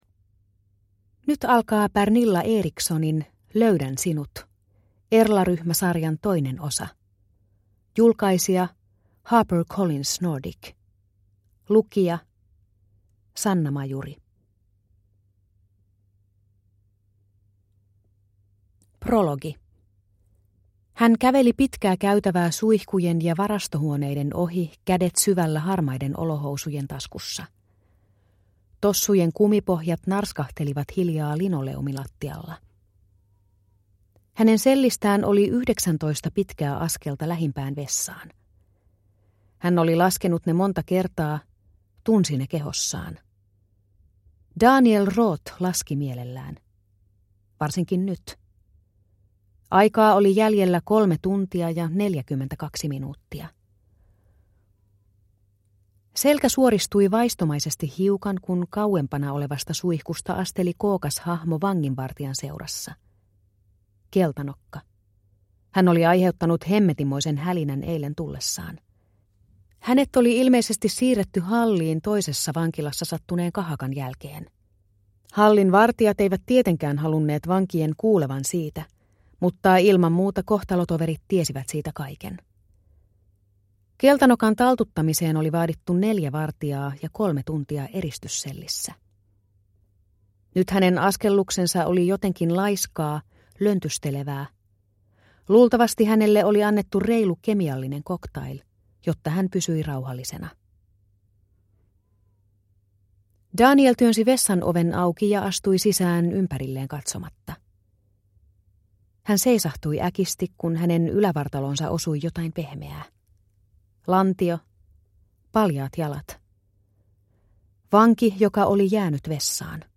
Löydän sinut – Ljudbok – Laddas ner